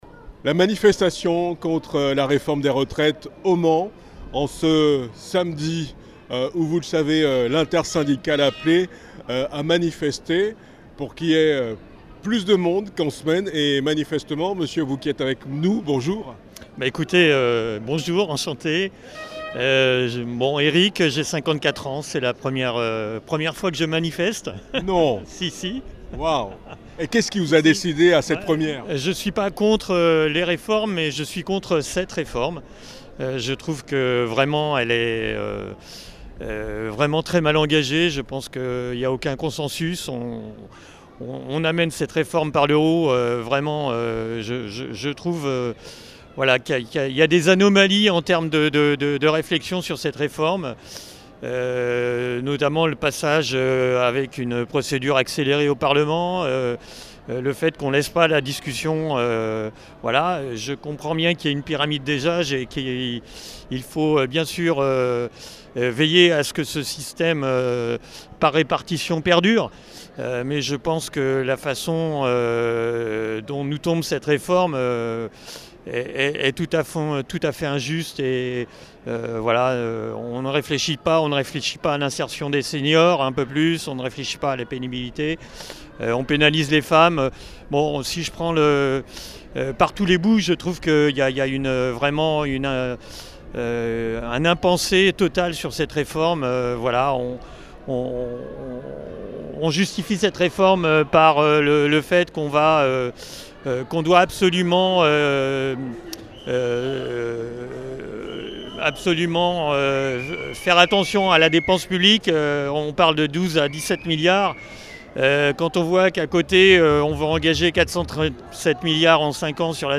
11 février 2023 : manifestation contre la réforme des retraites au Mans